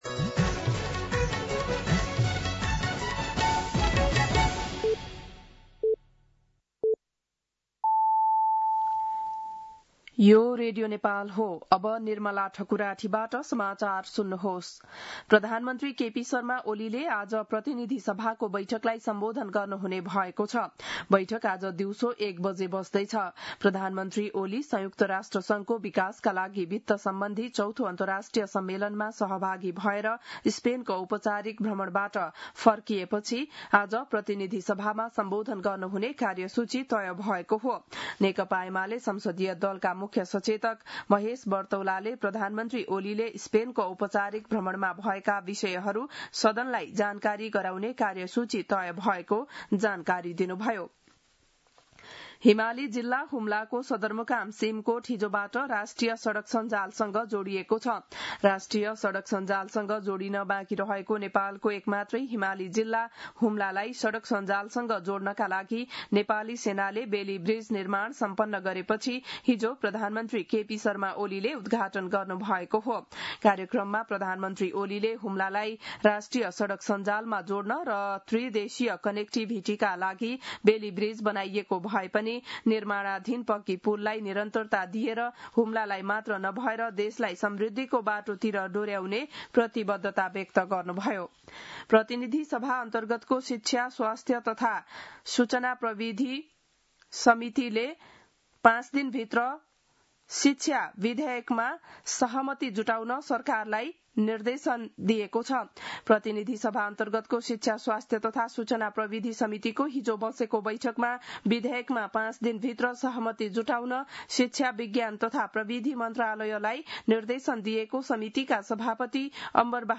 An online outlet of Nepal's national radio broadcaster
बिहान ११ बजेको नेपाली समाचार : २३ असार , २०८२